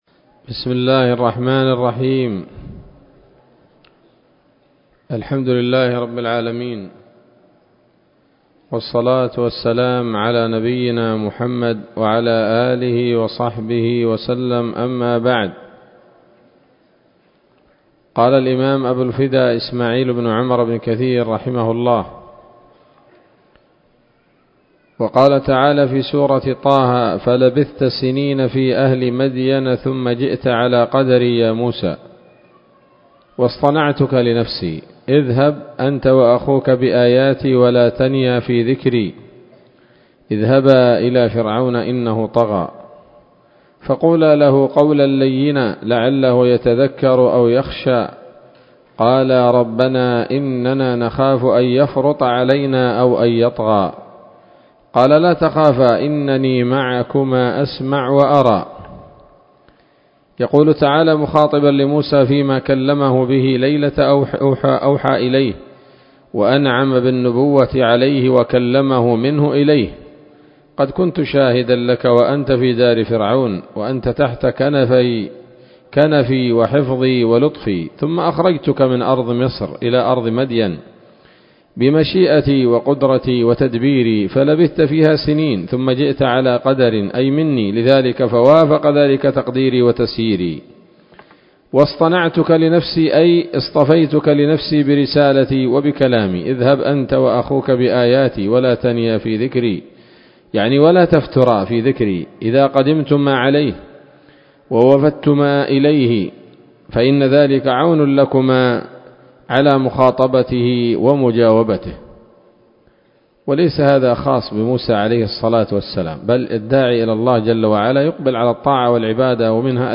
الدرس السابع والثمانون من قصص الأنبياء لابن كثير رحمه الله تعالى